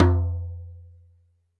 2 perc -bongoing.wav